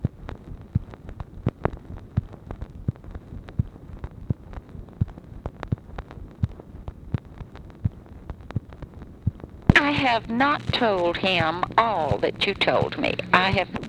Conversation
Secret White House Tapes